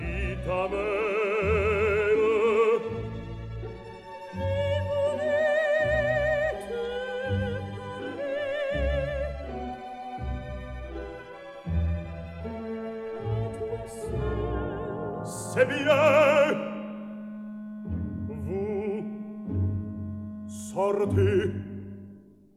soprano
tenor
baritone
bass